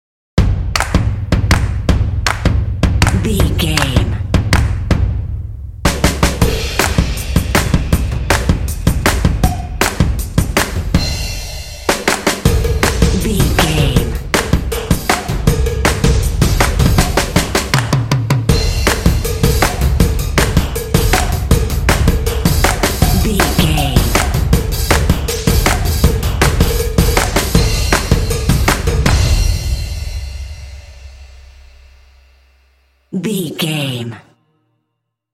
Epic / Action
Atonal
driving
motivational
determined
drums
drumline